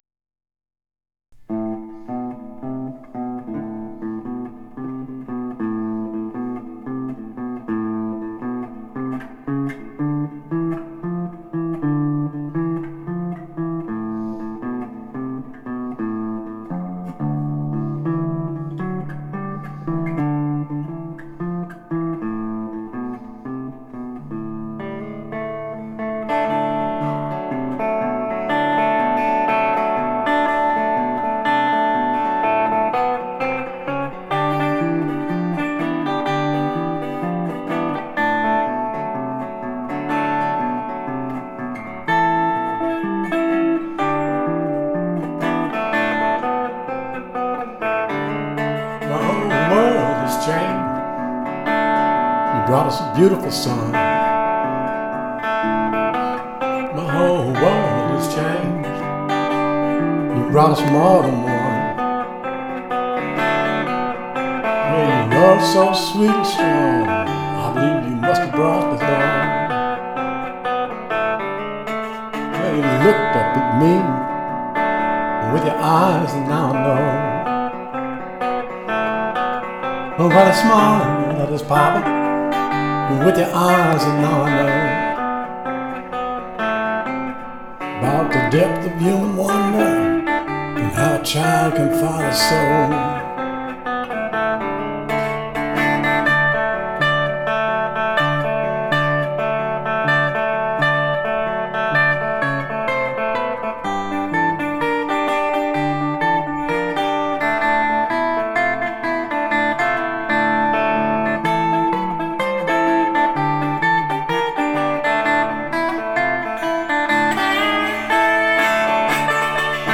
Song List Mantra Wines Open Mic
2023-05-31 Mantra Wines, Novato